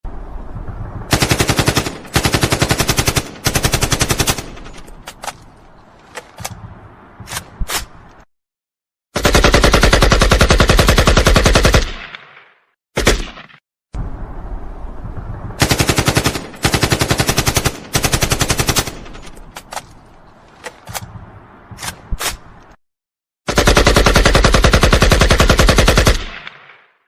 Nada Dering Suara Tembakan Ak 47
Kategori: Suara senjata tempur
Keterangan: Download nada dering suara tembakan AK 47, unduh suara tembakan AK-47 dalam format mp3 sebagai ringtone untuk WhatsApp, telepon...
nada-dering-suara-tembakan-ak-47-id-www_tiengdong_com.mp3